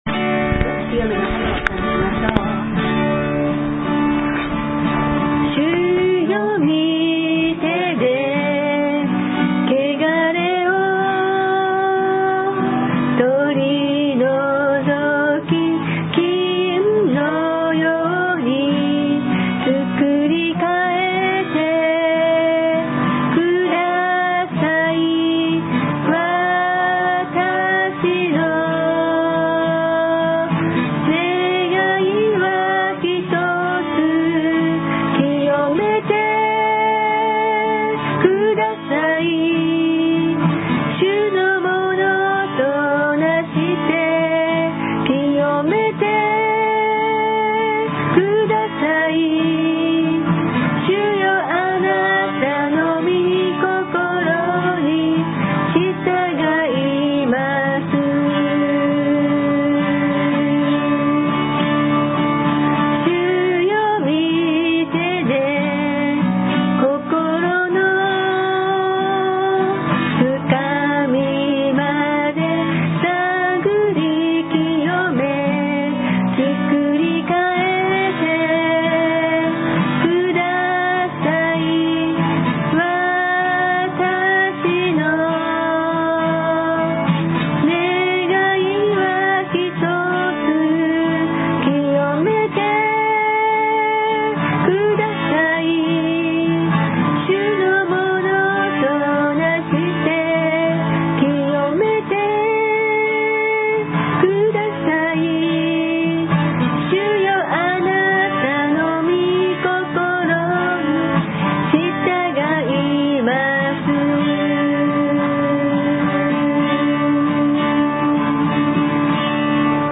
worship.MP3